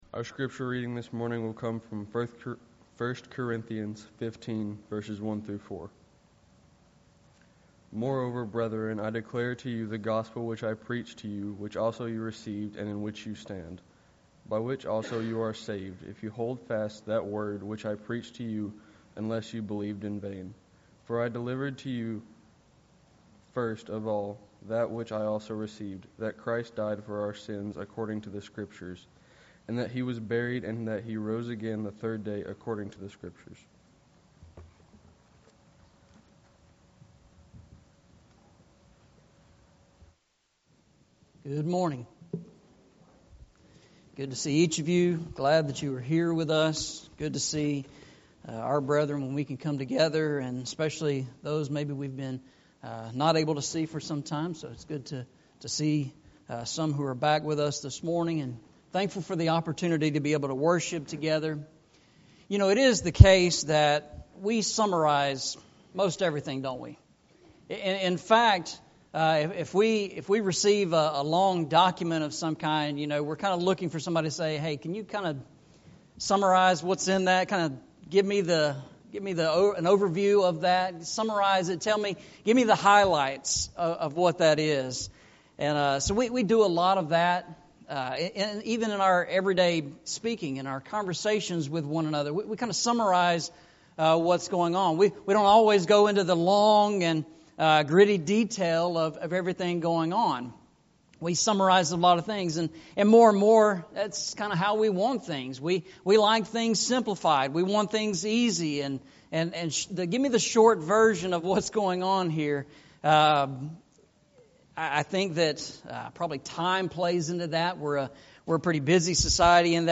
Series: Eastside Sermons Service Type: Sunday Morning